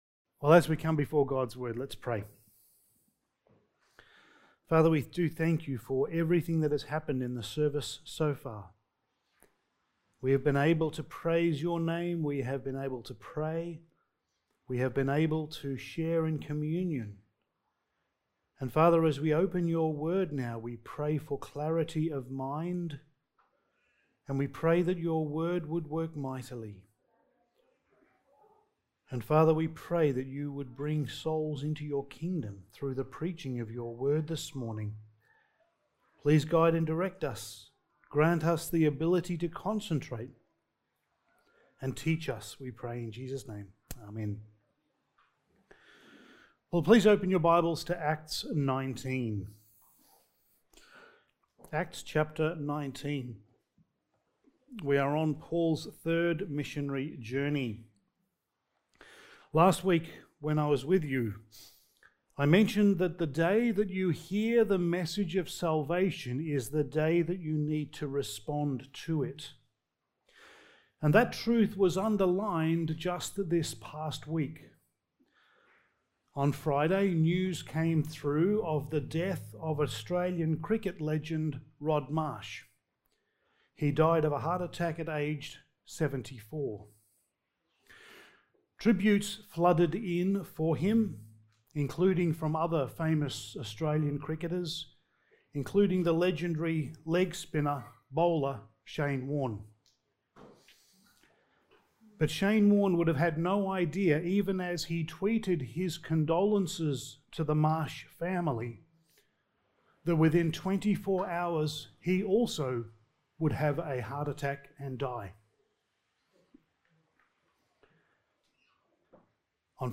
Passage: Acts 19:21-41 Service Type: Sunday Morning